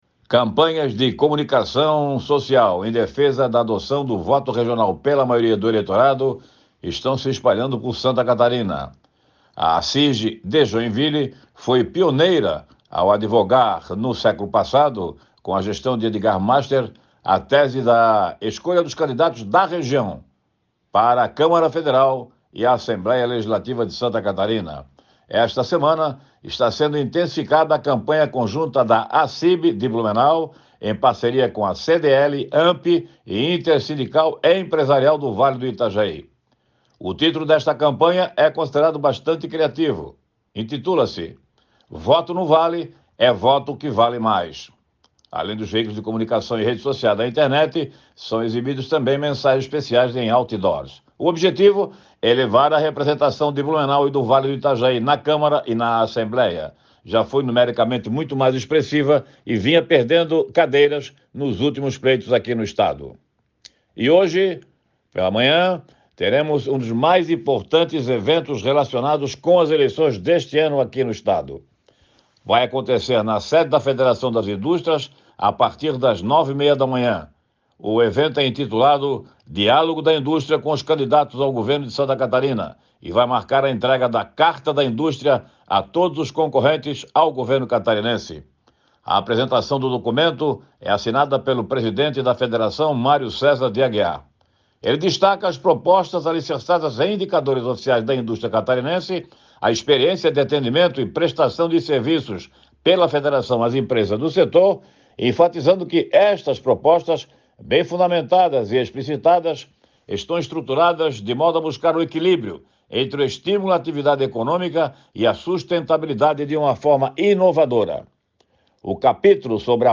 Jornalista comenta que o objetivo principal é levar representatividade das regiões catarinenses para as esferas federal e estadual